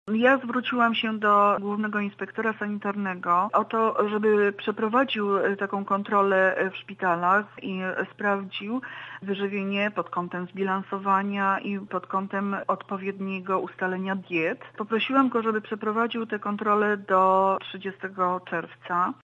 – mówi Rzecznik Praw Pacjenta, Krystyna Barbara Kozłowska.